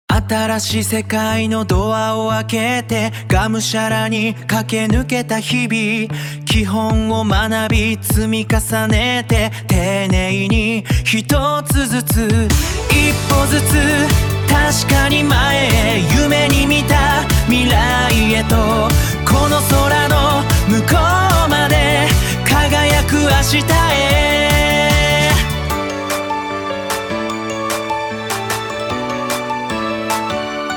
テキストで指示を出すだけで、歌詞の書き下ろしからメロディ、歌声の生成までをわずか数十秒で行なってくれます。
曲調は王道アイドルのような爽やかな曲調にしたいです。
ただ、日本語としての発音に違和感があるところや、何と言っているのか聞き取れないところもありました。